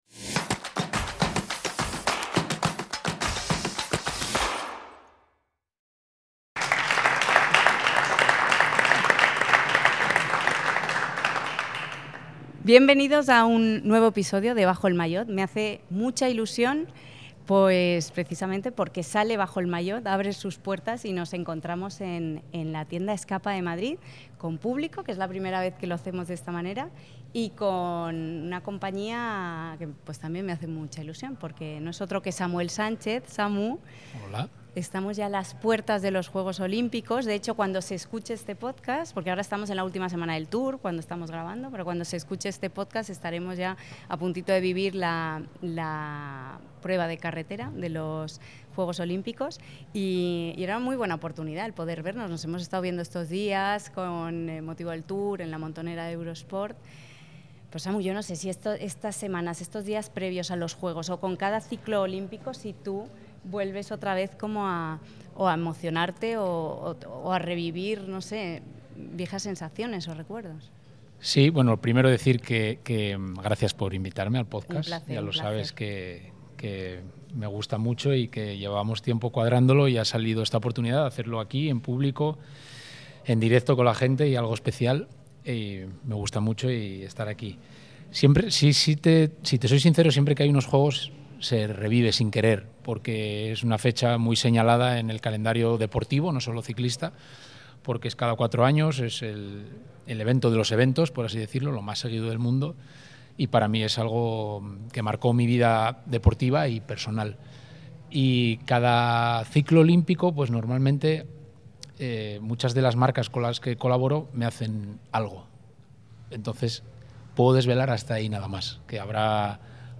Por primera vez grabamos con público en la tienda Escapa de Madrid. Con Samuel Sánchez miramos atrás a su oro olímpico en los Juegos de Pekín 2008, en el que fue el mejor año de la historia del ciclismo español.